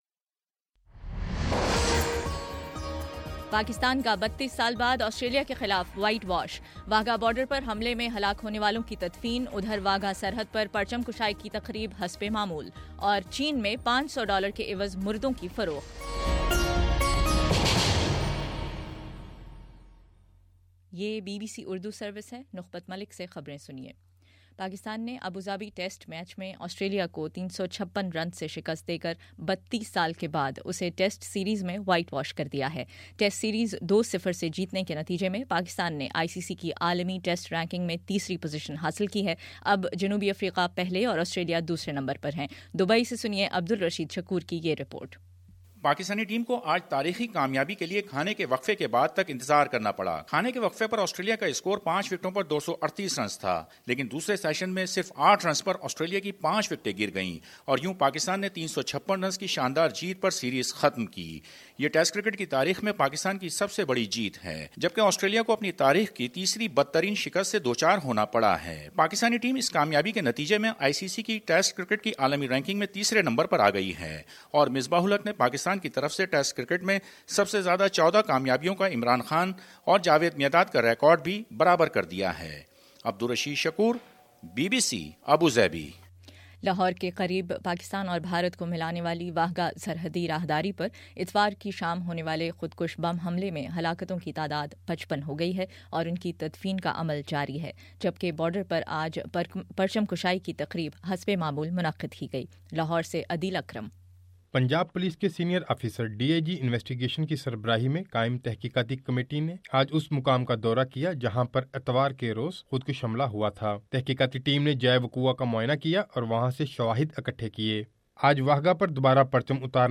نومبر03: شام چھ بجے کا نیوز بُلیٹن
دس منٹ کا نیوز بُلیٹن روزانہ پاکستانی وقت کے مطابق صبح 9 بجے، شام 6 بجے اور پھر 7 بجے۔